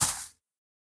Minecraft Version Minecraft Version 25w18a Latest Release | Latest Snapshot 25w18a / assets / minecraft / sounds / block / nether_sprouts / step2.ogg Compare With Compare With Latest Release | Latest Snapshot